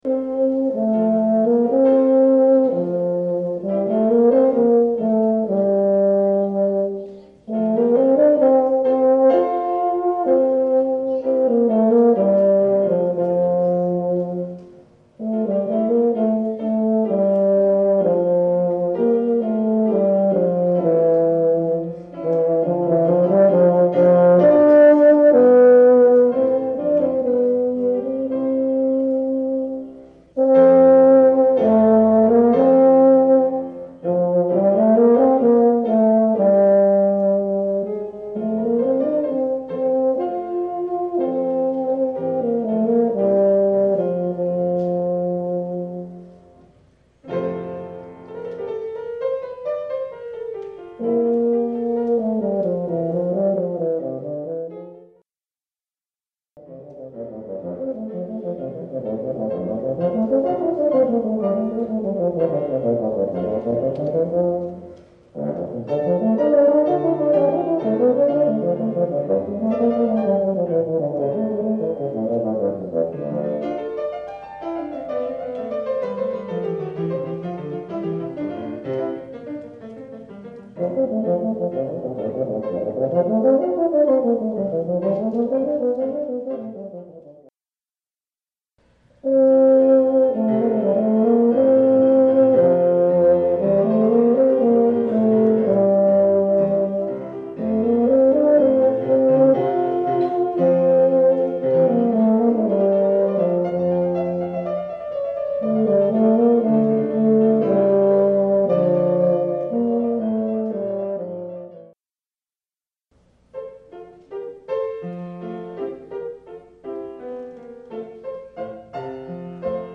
For Euphonium Solo
with Piano.